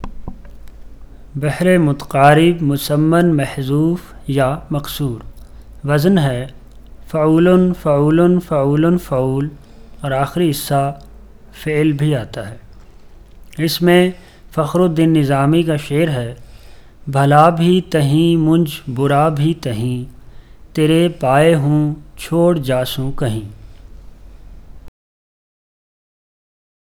Audio samples of the recitation of the Urdu metre